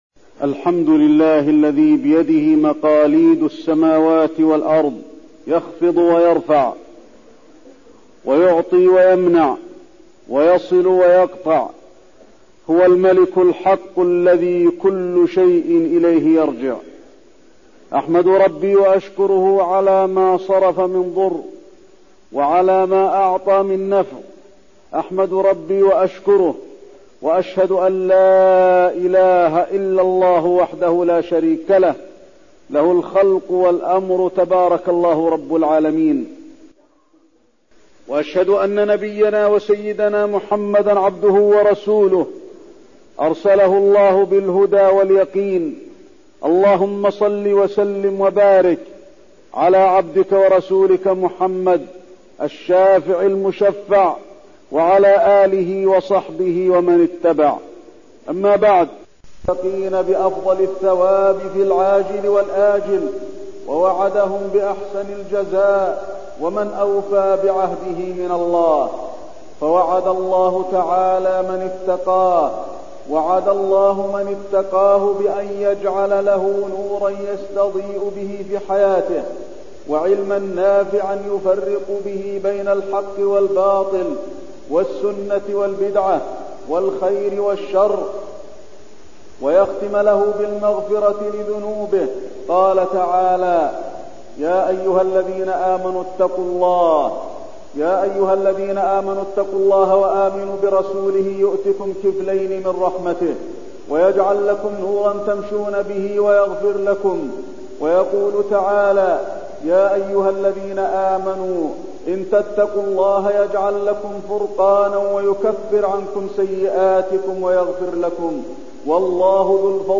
تاريخ النشر ٢٣ شوال ١٤٠٧ هـ المكان: المسجد النبوي الشيخ: فضيلة الشيخ د. علي بن عبدالرحمن الحذيفي فضيلة الشيخ د. علي بن عبدالرحمن الحذيفي التقوى